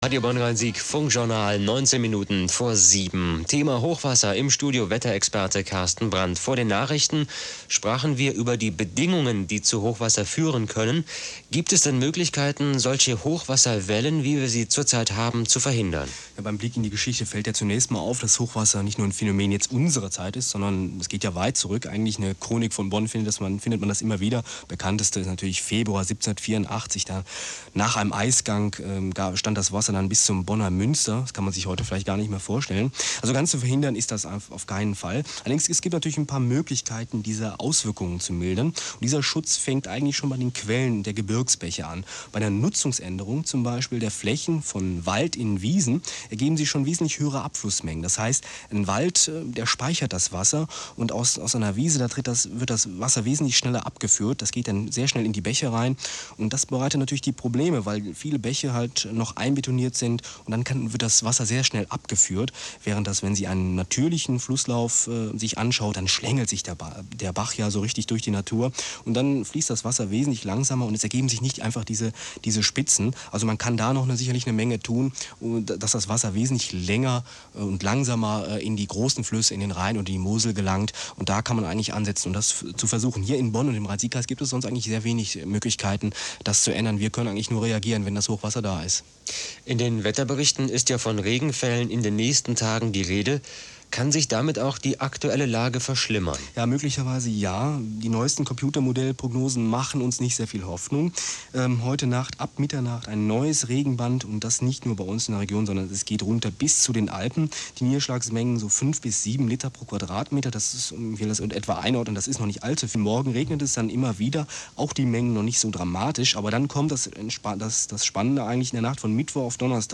Die O-Töne zur Vorhersage von 1995 auf Radio Bonn/Rhein-Sieg könnt Ihr hier Euch hier nochmals anhören!